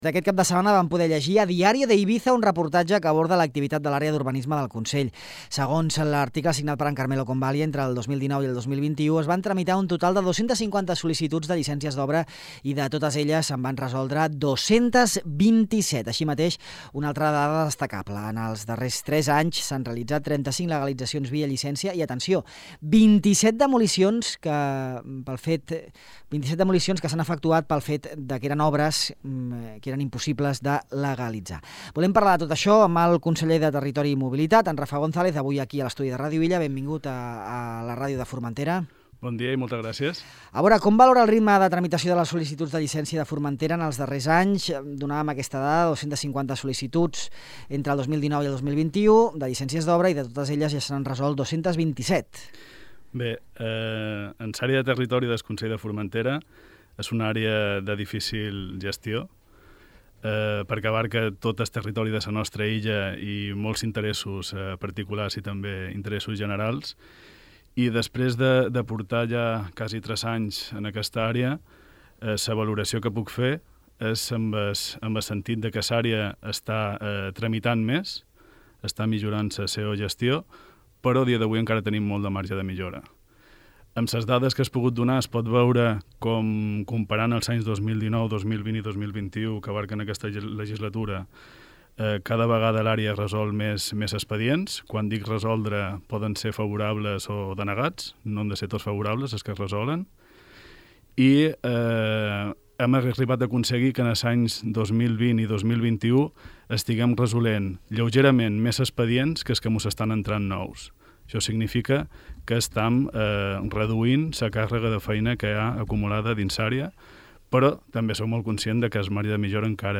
El conseller de Territori i Mobilitat, Rafa González, ha repassat a Ràdio Illa l’activitat urbanística de la seva àrea i ha declarat que els temps de resolució dels tràmits, de mitjana uns tres anys per a una llicència d’obra major nova en rústic, presenten “un marge de millora”, de manera que espera l’administració els pugui abordar amb “més agilitat”.